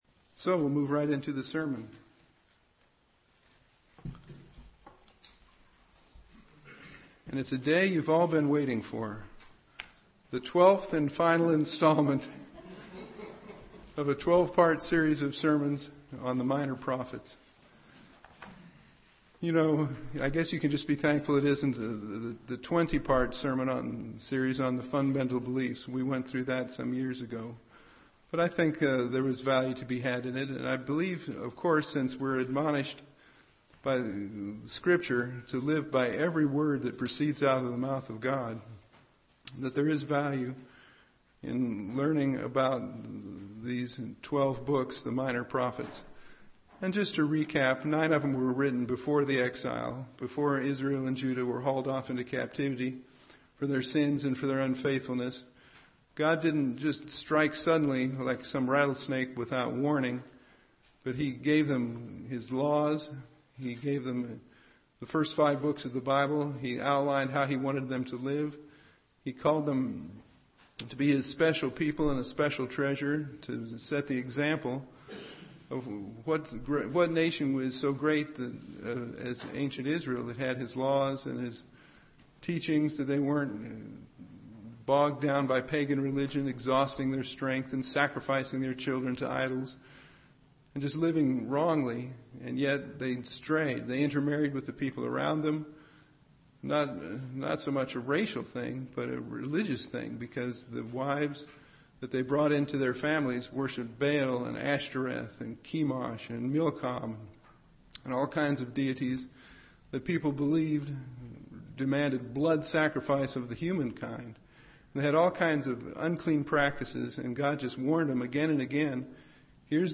12th and final sermon in a series on the Minor Prophets and what they offer Christians today.